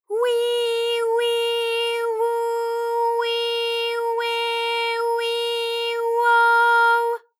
ALYS-DB-001-JPN - First Japanese UTAU vocal library of ALYS.
wi_wi_wu_wi_we_wi_wo_w.wav